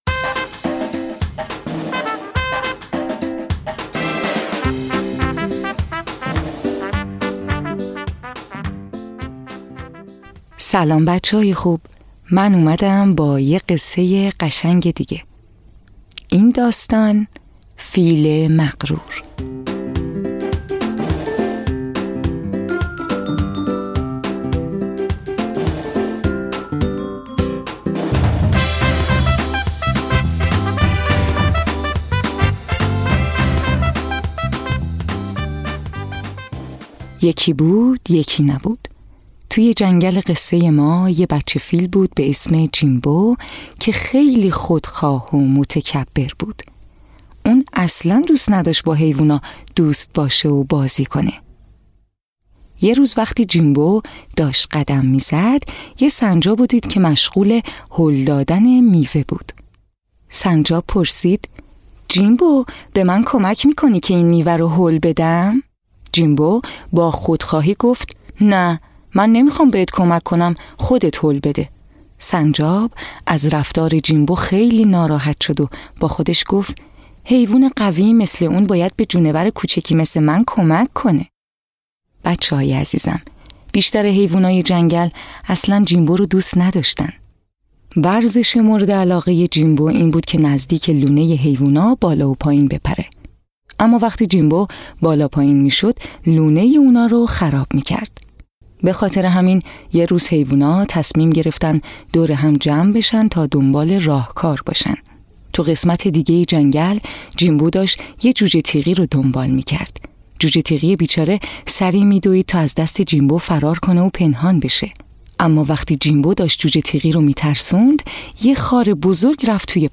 قصه های کودکانه صوتی – این داستان: فیل مغرور
تهیه شده در استودیو نت به نت